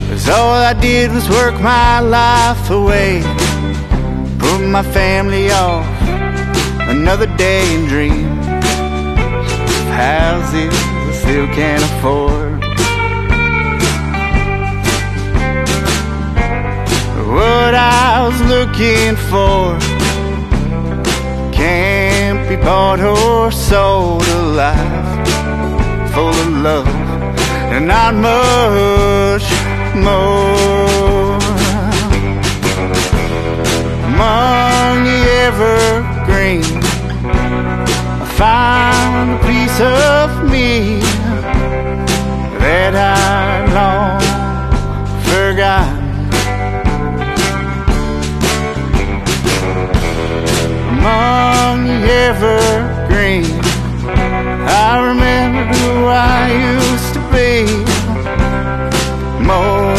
Had a great time recording this one in the studio.